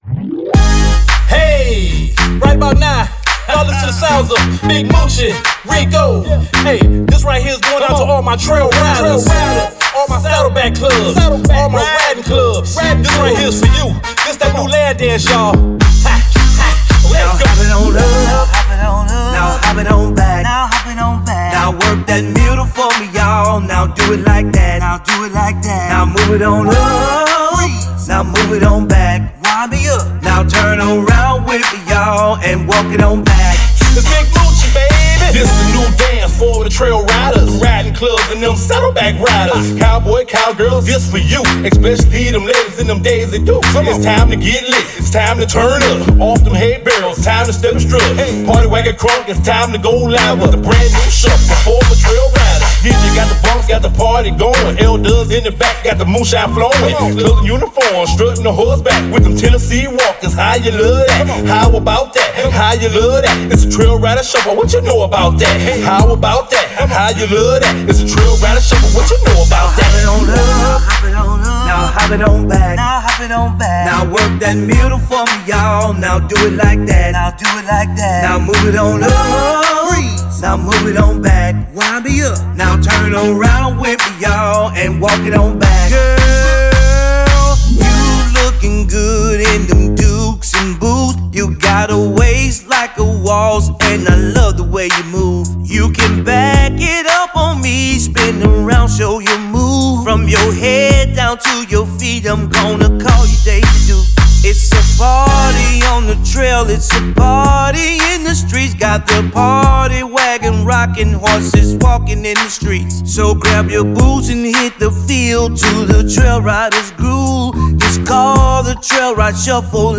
Soul